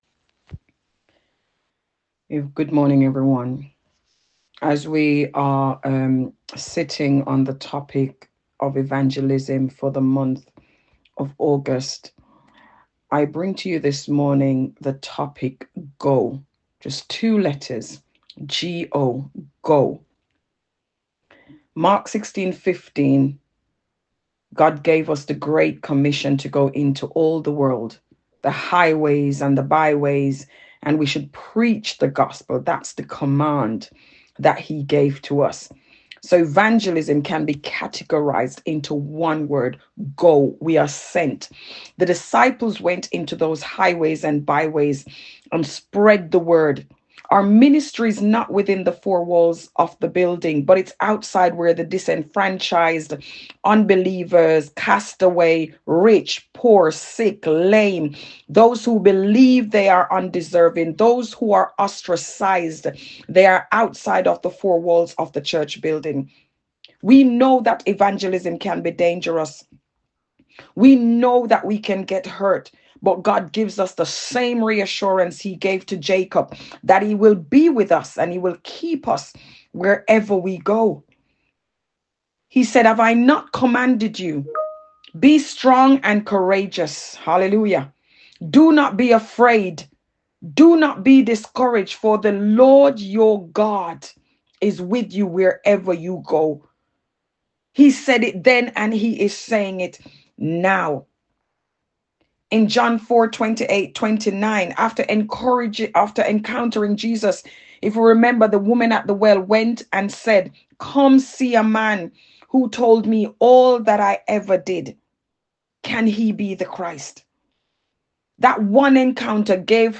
Today's Testimony